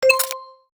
UI_SFX_Pack_61_28.wav